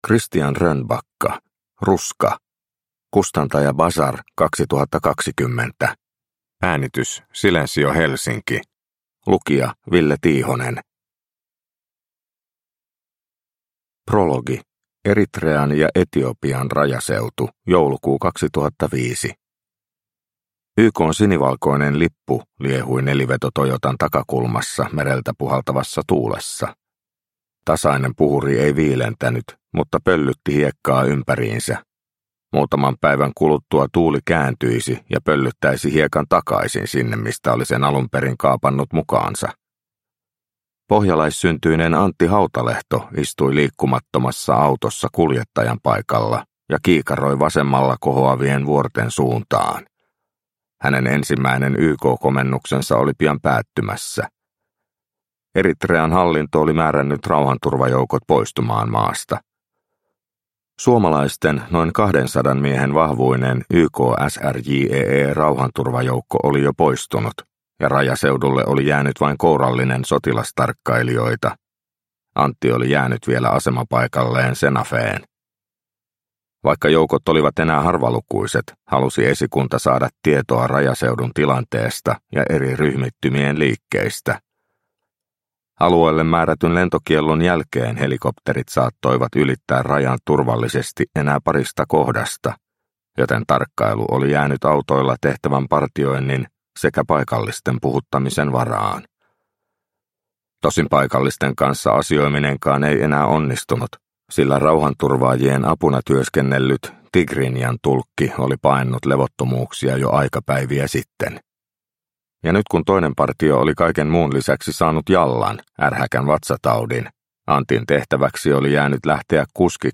Ruska – Ljudbok – Laddas ner